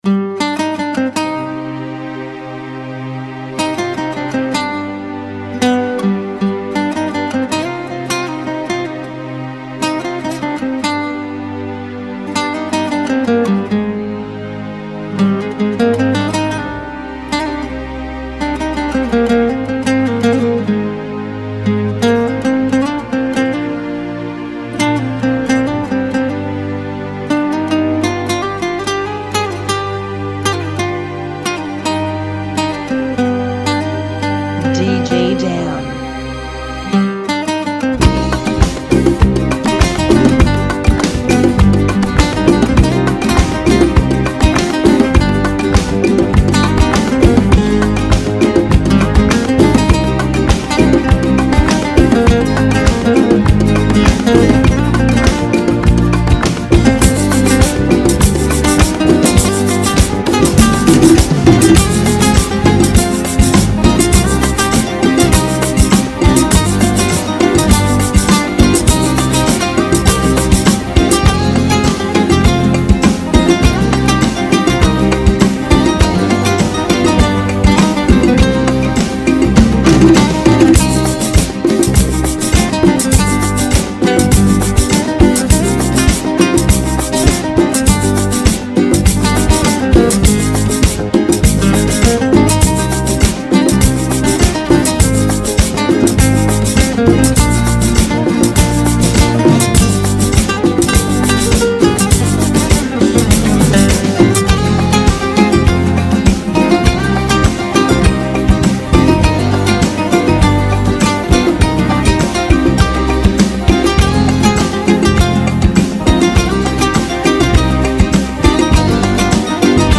101 BPM
Genre: Salsa Remix